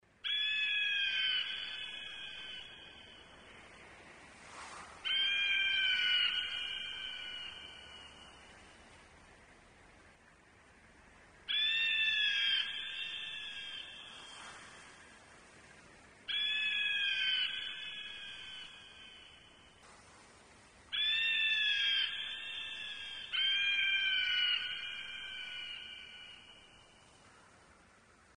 Звуки крика орла
На этой странице собраны звуки криков орлов – от пронзительных охотничьих кличей до переклички в полете.